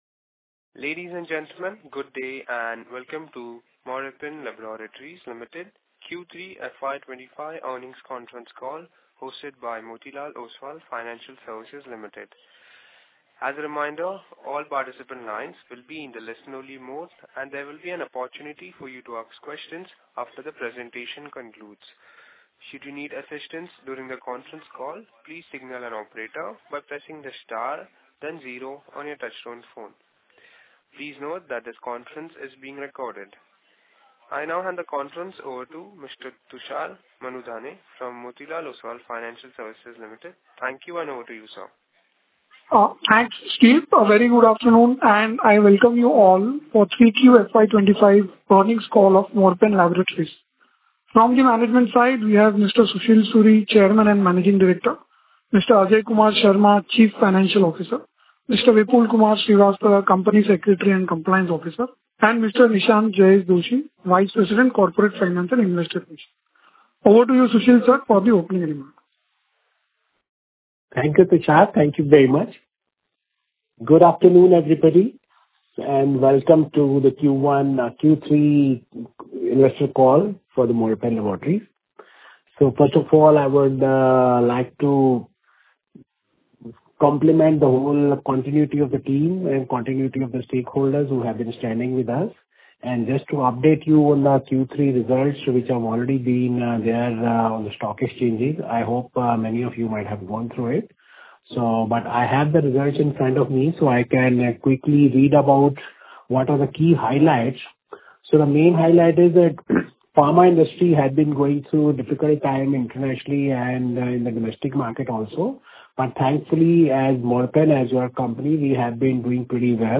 Investor Earning Call Q3 FY 2025
Investor-Earning-Call-q3-fy-2025.mp3